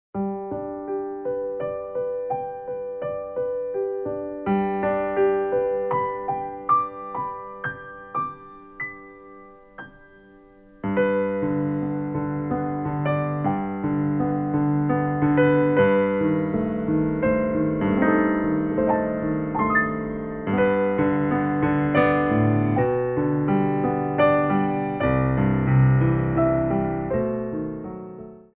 Adage
3/4 (8x8)